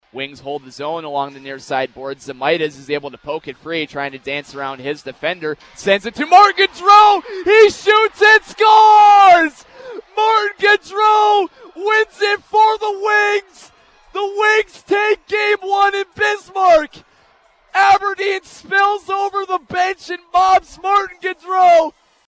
on the play-by-play: